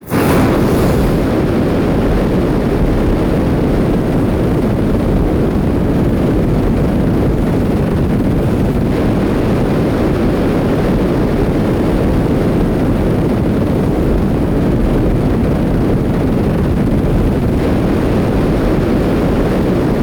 cookoff_low_pressure.ogg